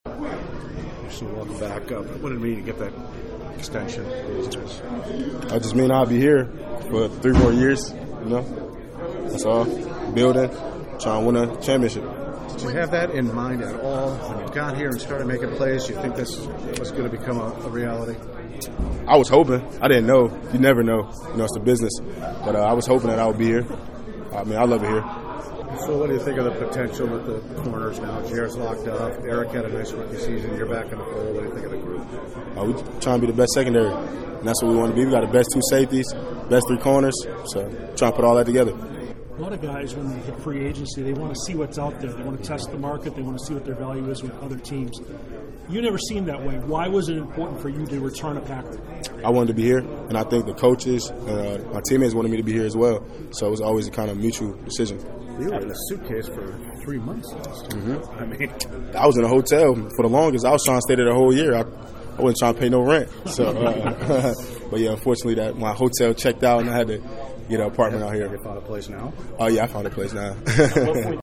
Fresh off getting a three year, 21 million dollar contract this off-season, Douglas met reporters in the locker room after practice.